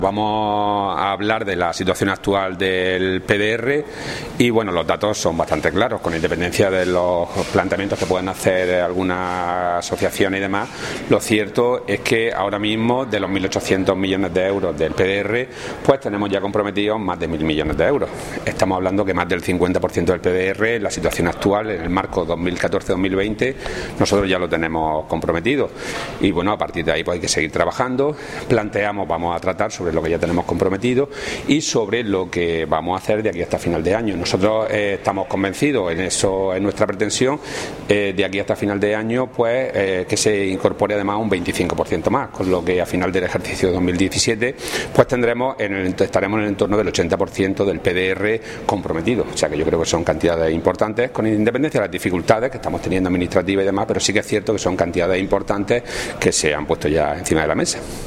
Declaraciones de Rodrigo Sánchez Haro sobre ejecución del PDR de Andalucía 2014-2020